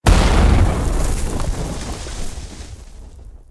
grasping_hands_start.ogg